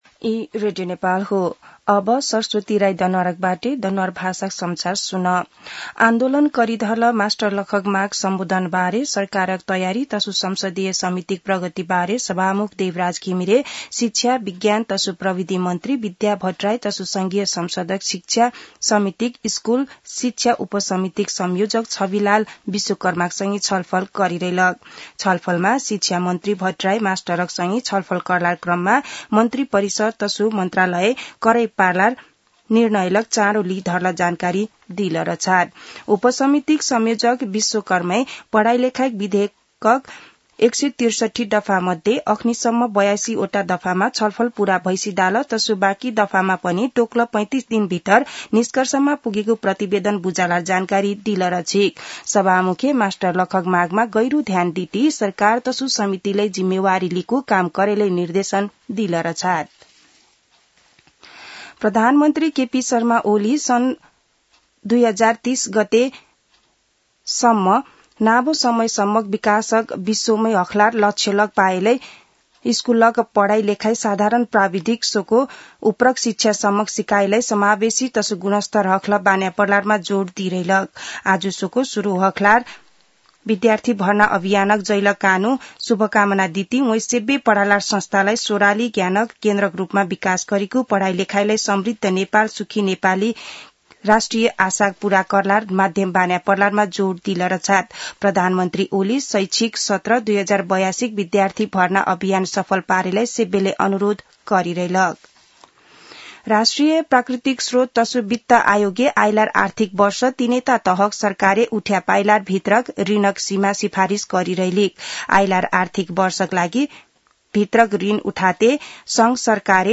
दनुवार भाषामा समाचार : २ वैशाख , २०८२
danuwar-news-1-4.mp3